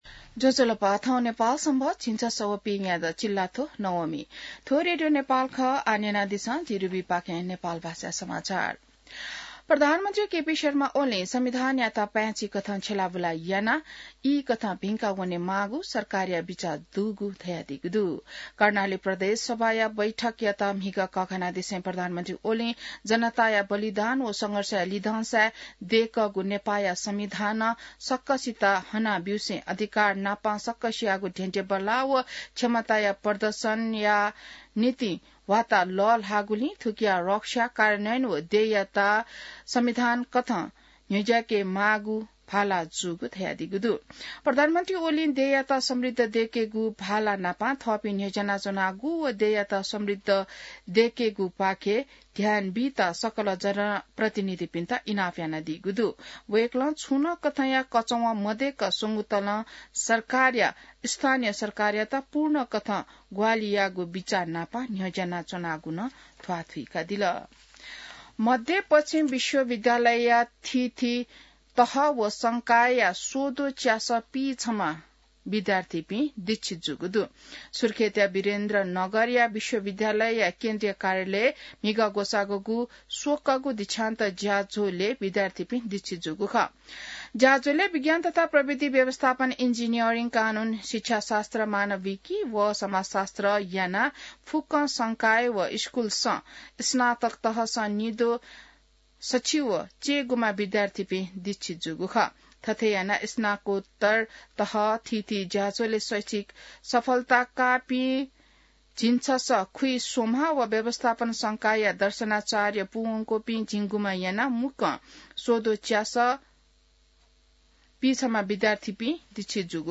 नेपाल भाषामा समाचार : २५ फागुन , २०८१